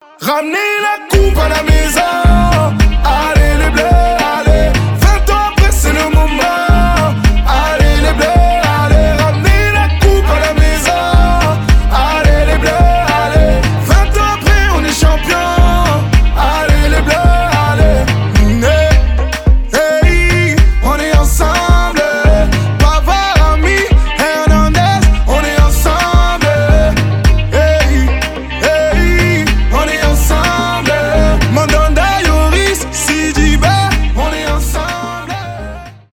хип-хоп
поп